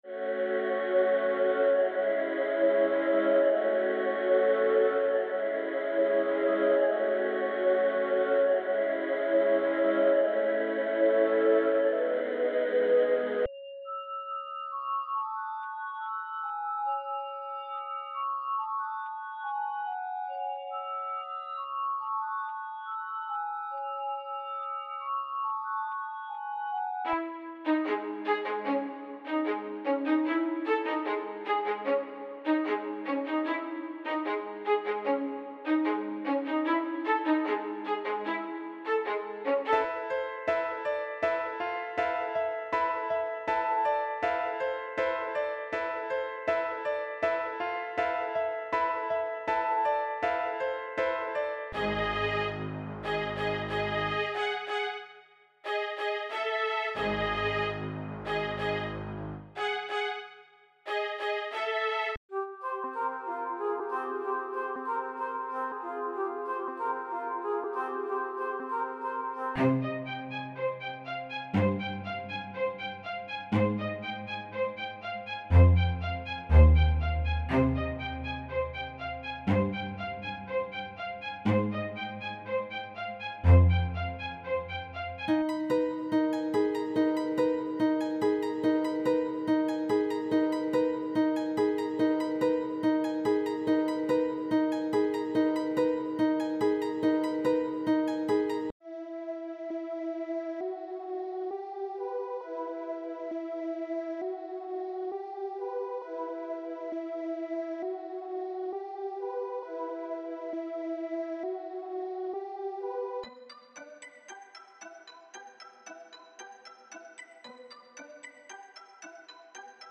• 16 Melody Loops